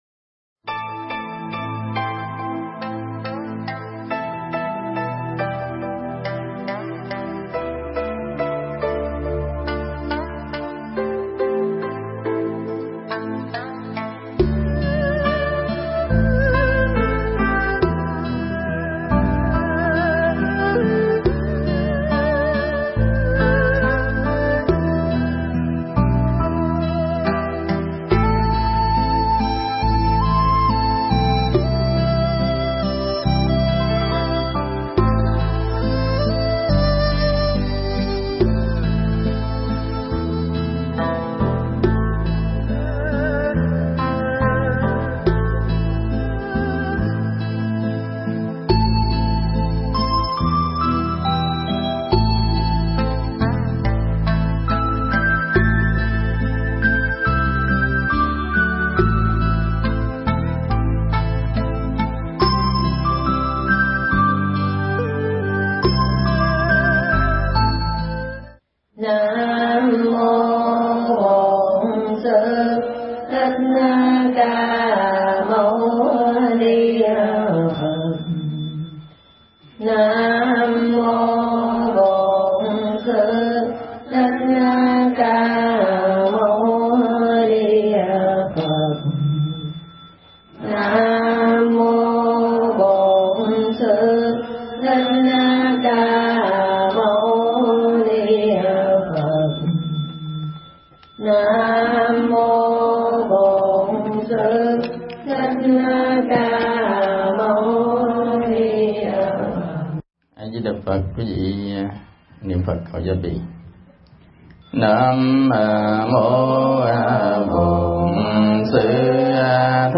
Mp3 Thuyết Pháp Kinh Tứ Diệu Đế